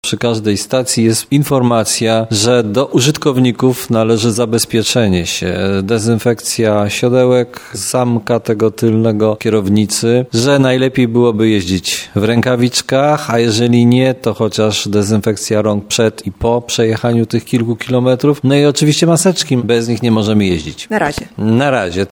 Wypożyczając rower trzeba także pamiętać o wymogach związanych z zachowaniem bezpieczeństwa sanitarnego. Mówi prezydent Tarnobrzega, Dariusz Bożek.